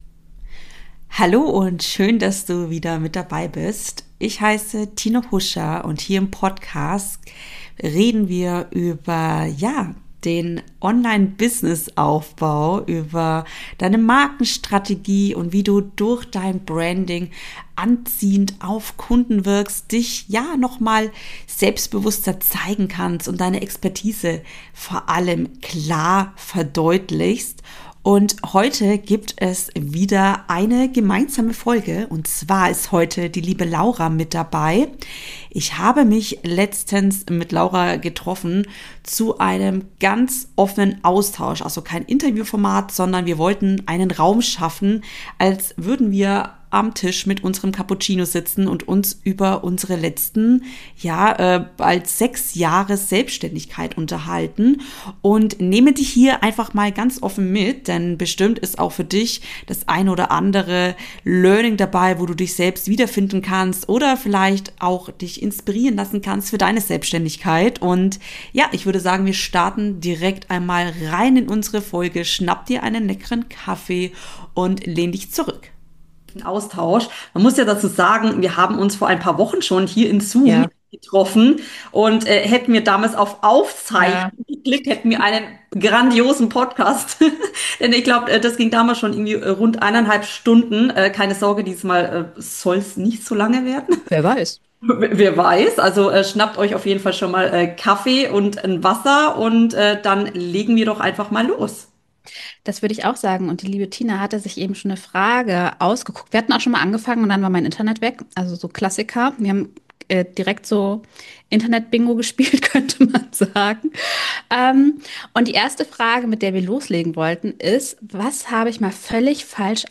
in Zoom getroffen und sprechen bei einer Tasse Kaffee ganz offen über unser Business.